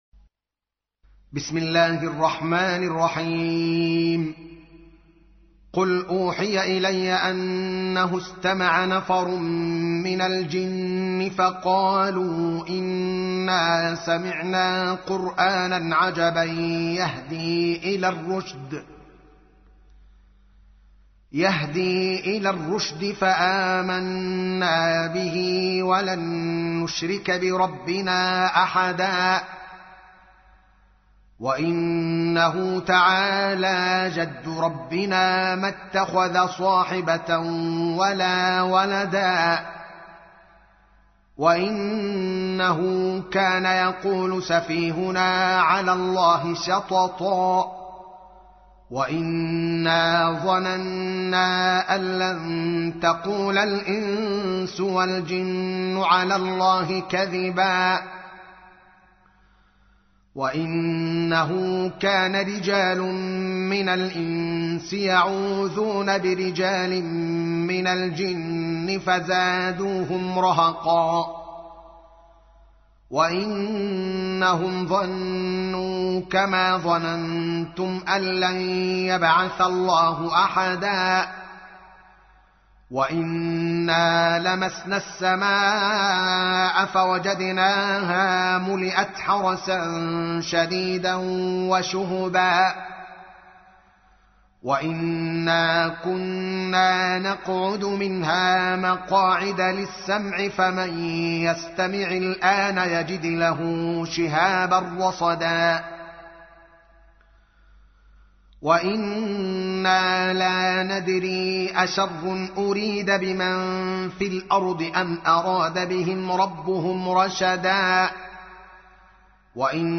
تحميل : 72. سورة الجن / القارئ الدوكالي محمد العالم / القرآن الكريم / موقع يا حسين